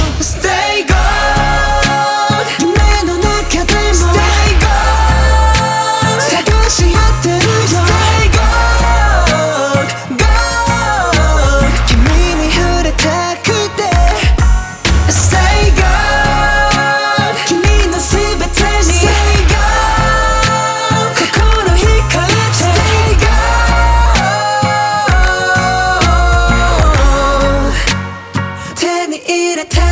Genre: K-Pop